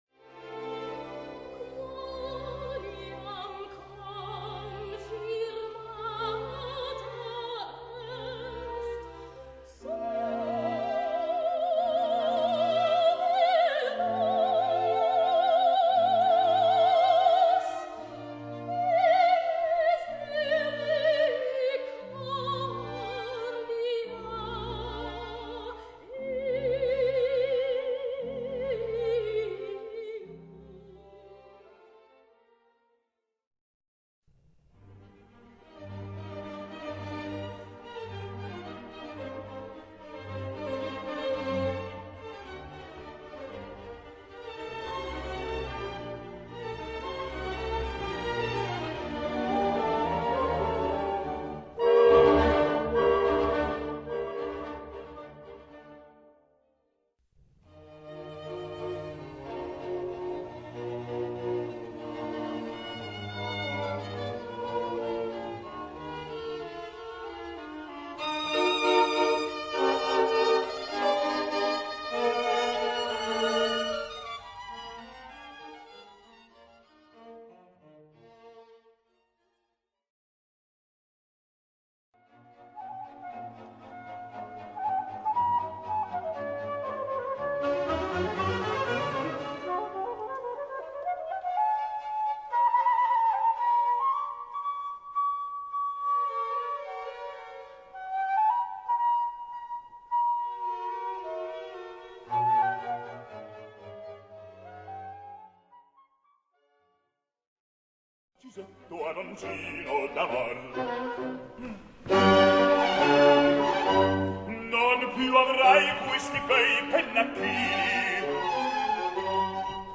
Ascolta, in sequenza, cinque frammenti di altrettanti composizioni di Mozart e completa gli spazi con i numeri relativi alle definizioni: